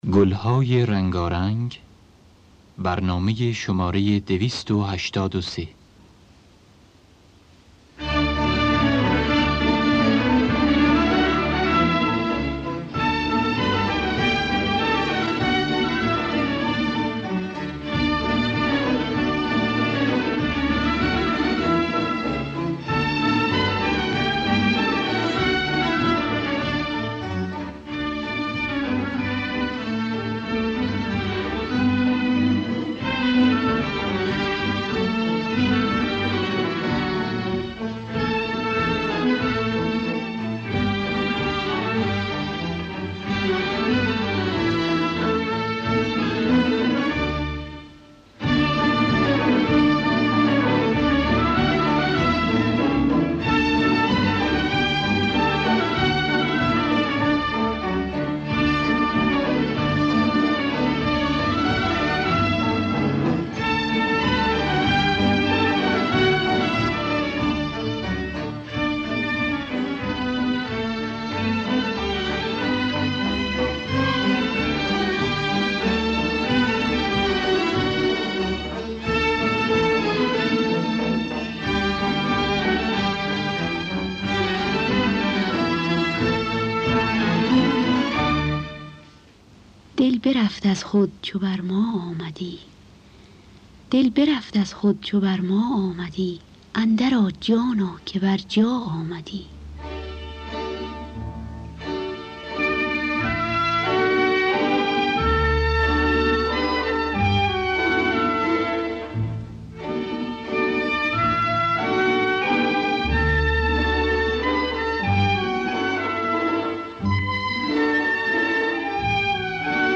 دانلود گلهای رنگارنگ ۲۸۳ با صدای عبدالوهاب شهیدی در دستگاه ماهور.